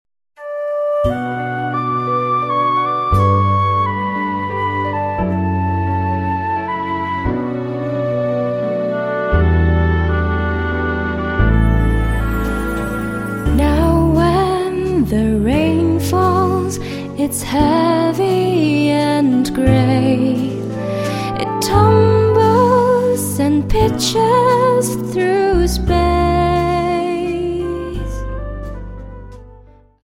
Slow Waltz 28 Song